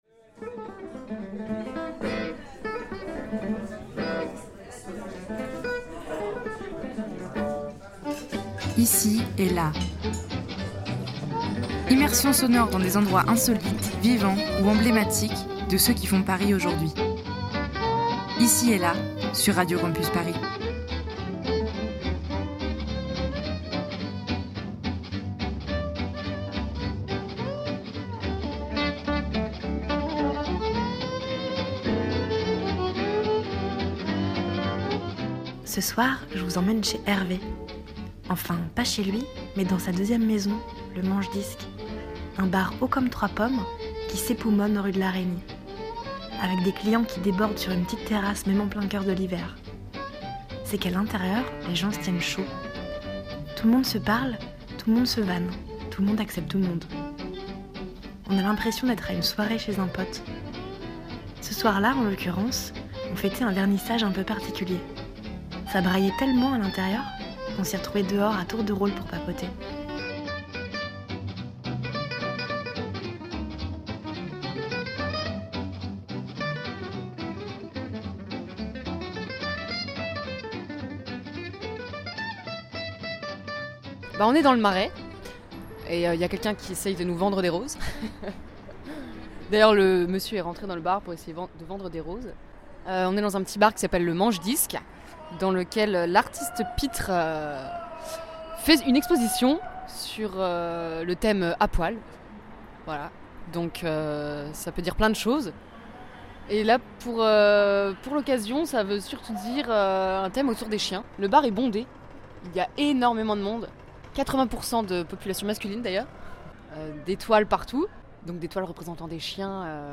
Un bar haut comme trois pommes qui s'époumone rue de la Reynie, dans le Marais. Ici, ça cause, ça rigole et ça se tape dans le dos. Comme l'endroit est exigu et souvent blindé, les clients débordent sur la terrasse. Rencontre avec quelques-uns d'entre eux, un soir de vernissage un peu particulier...